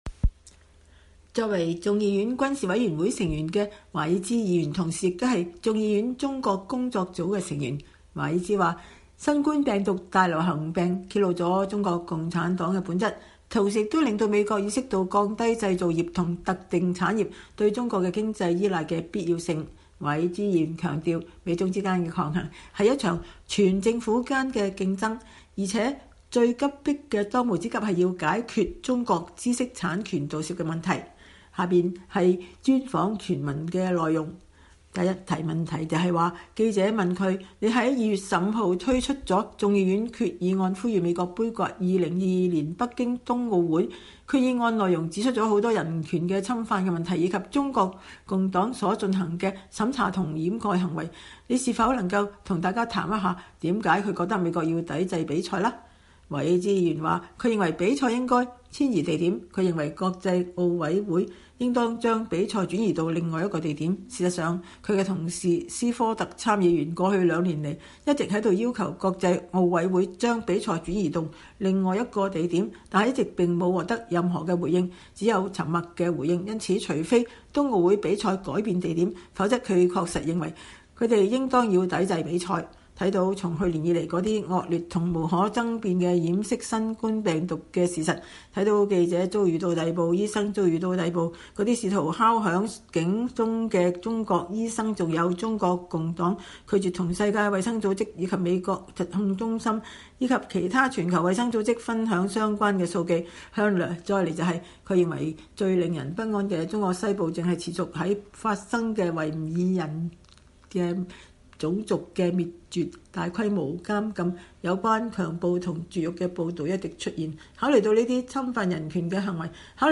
專訪共和黨聯邦眾議員華爾茲：“中共不應享有舉辦奧運的殊榮和利益”